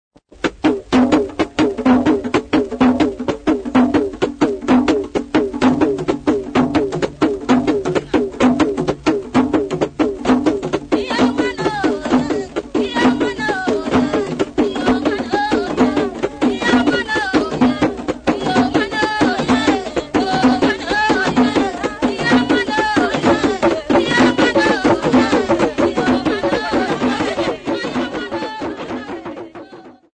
TP1545-XYZ4974a.mp3 of Drum Rhythm